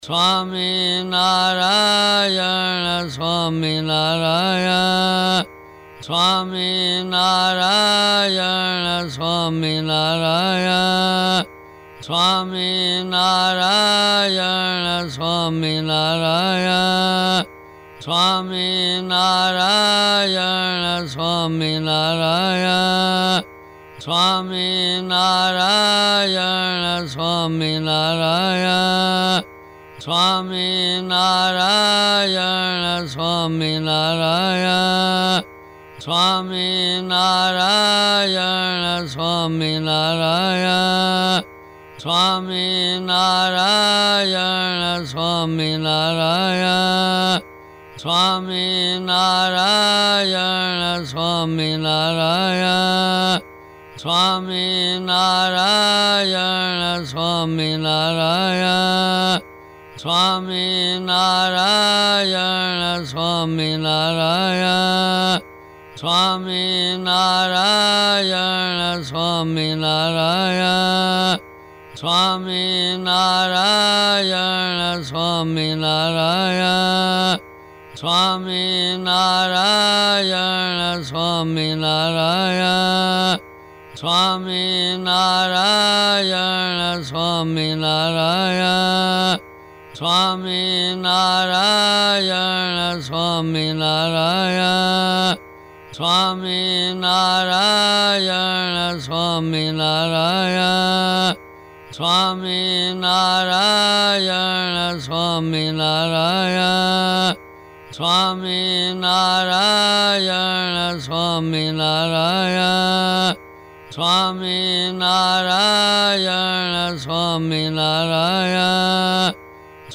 Swaminarayan Dhoon